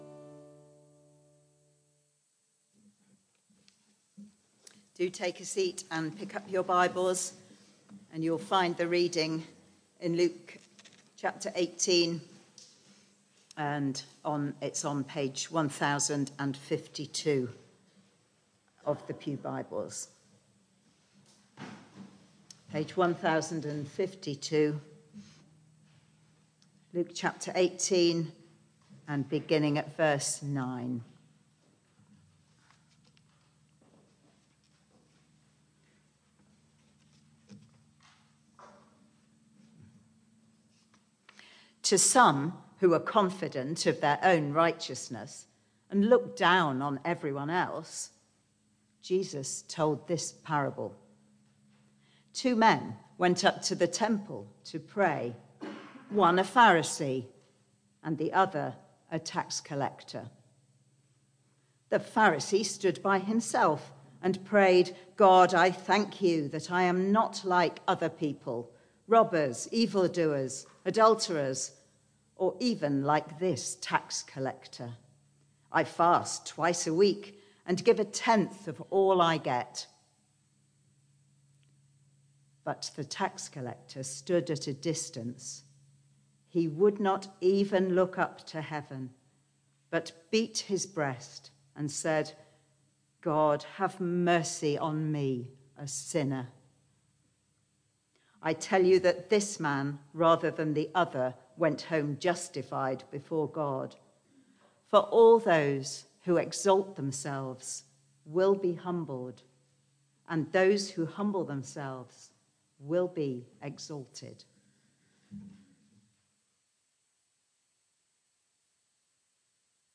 Barkham Morning Service
Passage: Luke 18:9-14 Series: Parables of Jesus Theme: Full Service Reading and Sermon